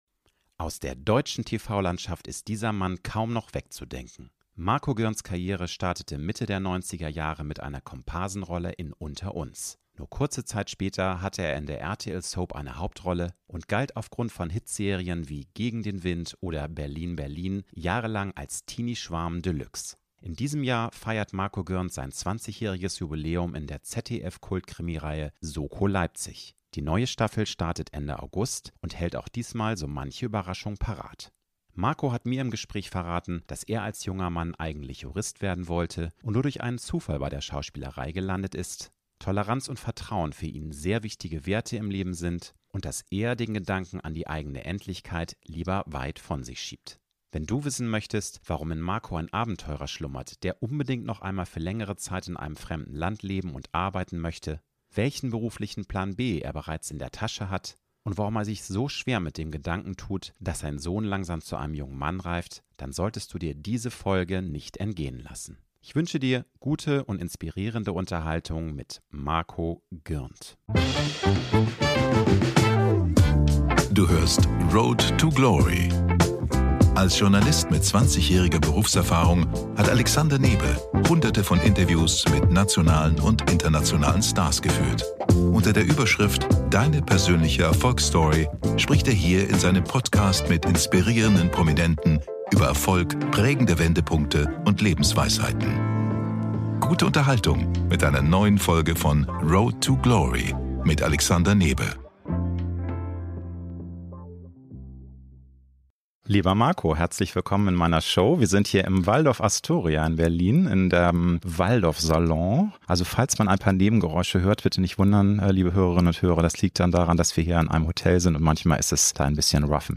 ~ Road to Glory - Promi-Talk